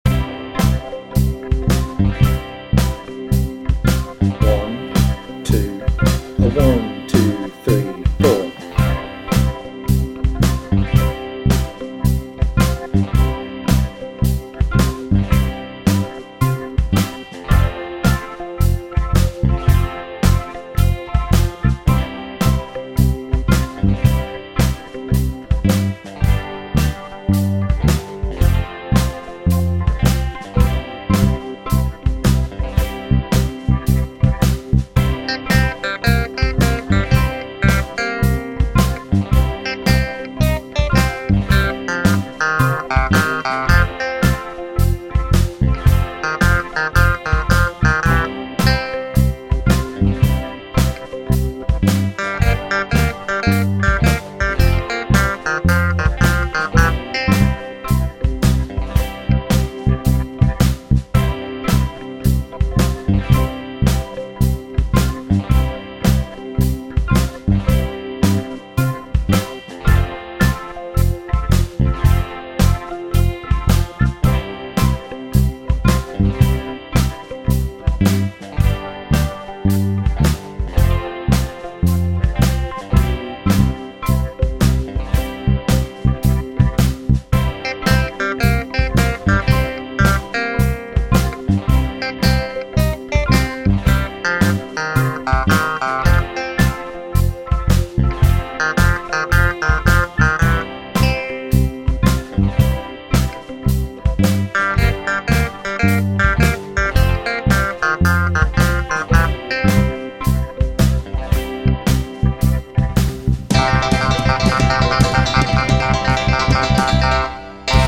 Backing track
ukulele_blues_bck_trk.mp3